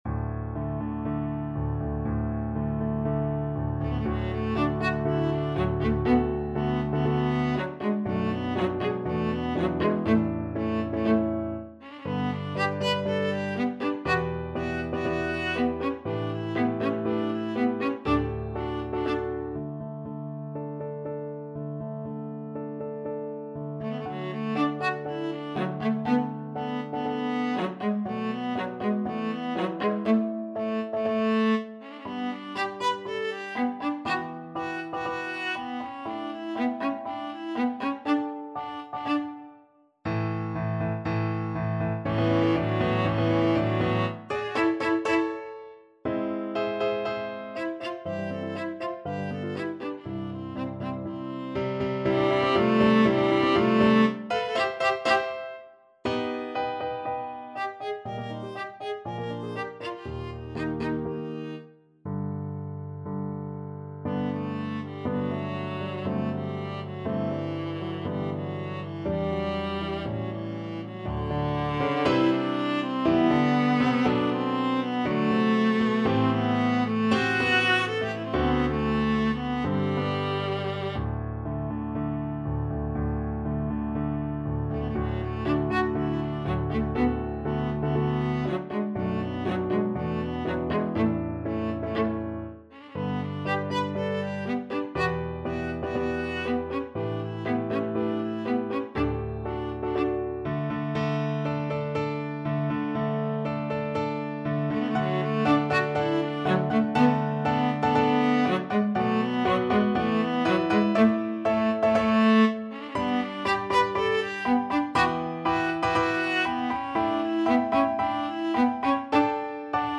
~ = 120 Tempo di Marcia un poco vivace
Classical (View more Classical Viola Music)